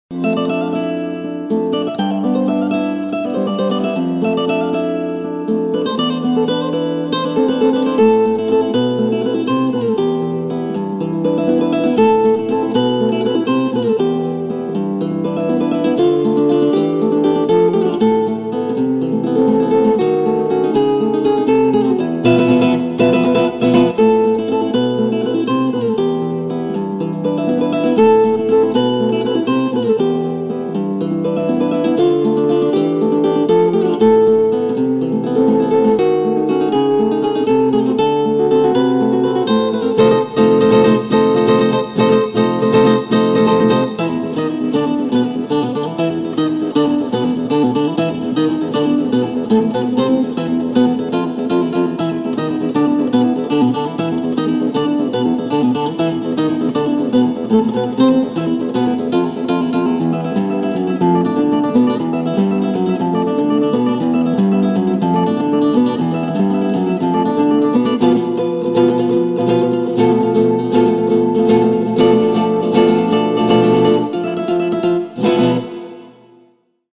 - Pieces for guitar duo -